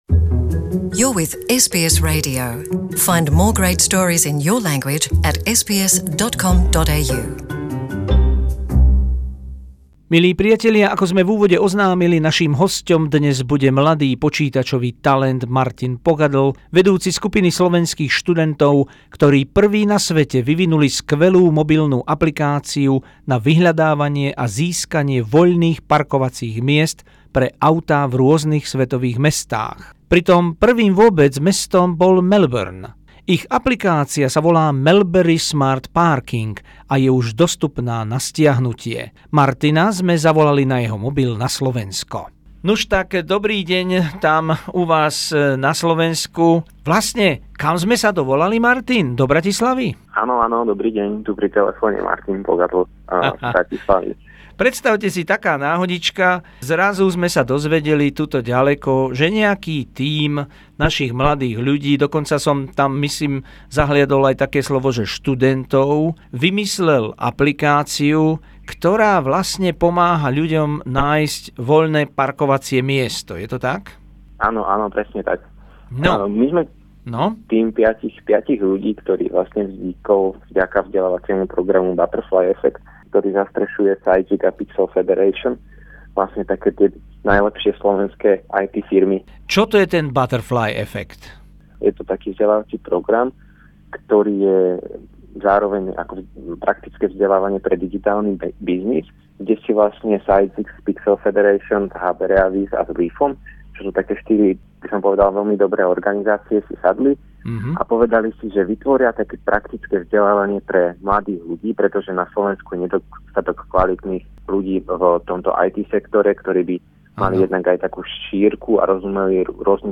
Náš rozhovor